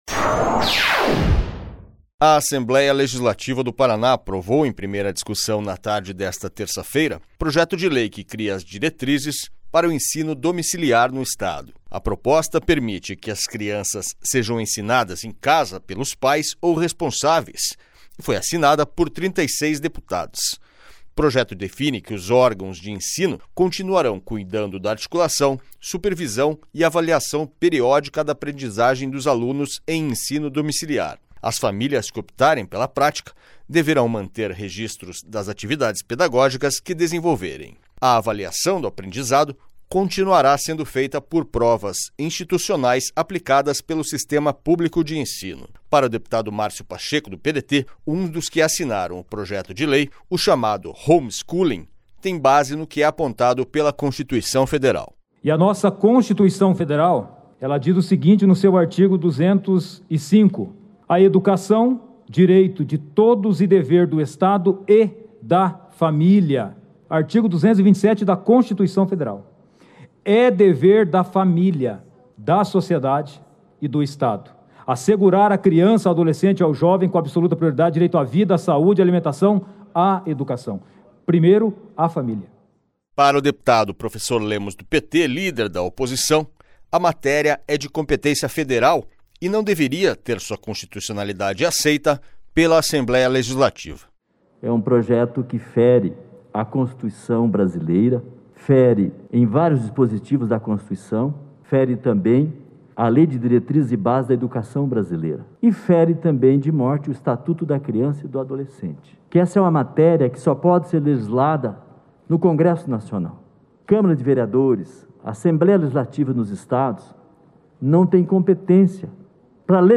SONORA MÁRCIO PACHECO